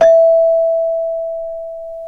CELESTE 2 E4.wav